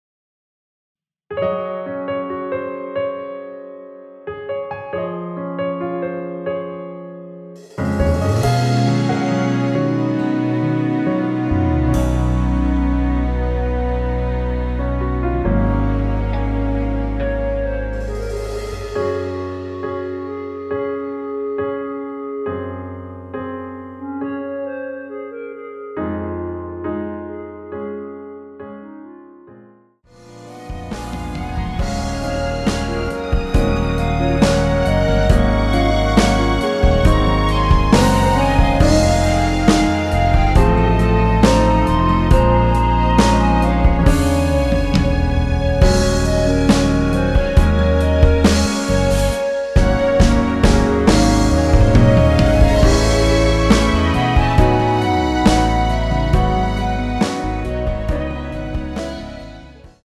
원키에서(-2)내린 1절후 후렴으로 진행 되는 멜로디 포함된 MR입니다.(미리듣기 확인)
Db
노래방에서 노래를 부르실때 노래 부분에 가이드 멜로디가 따라 나와서
앞부분30초, 뒷부분30초씩 편집해서 올려 드리고 있습니다.
중간에 음이 끈어지고 다시 나오는 이유는